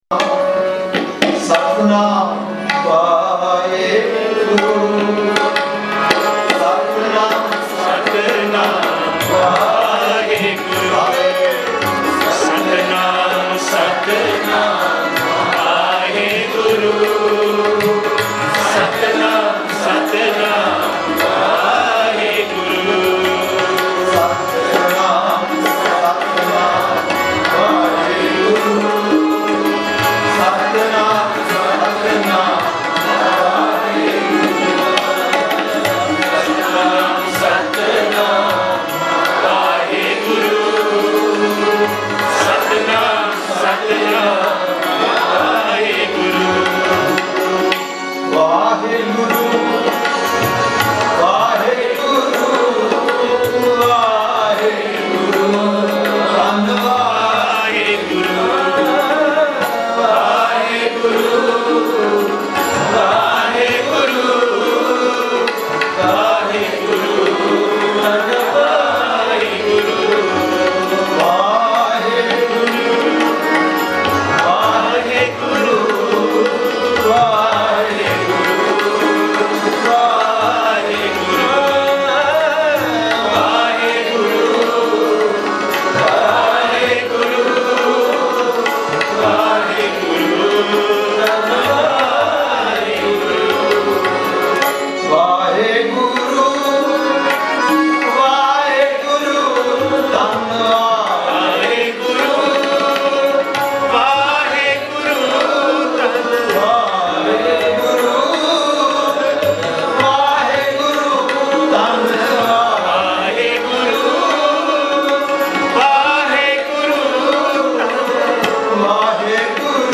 leading simran at Malacca Barsi 2012